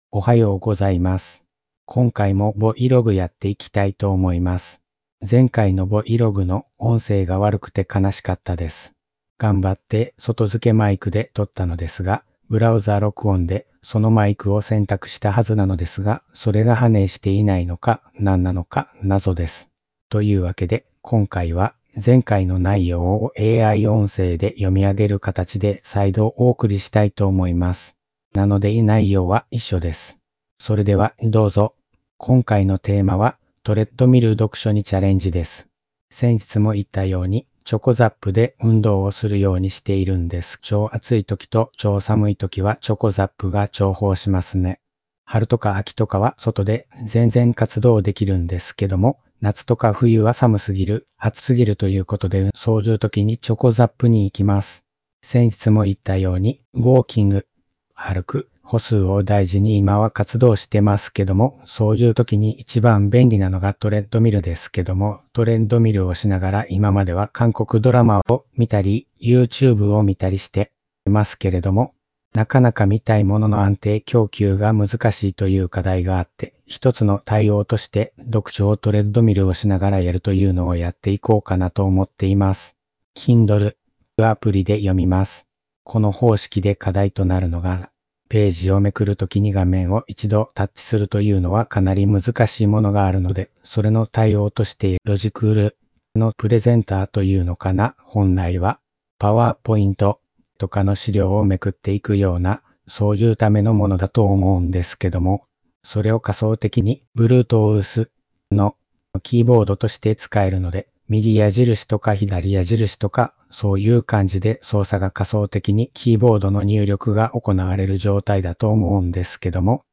R500s LASER PRESENTATION REMOTE#AIボイス
Audio Channels: 2 (stereo)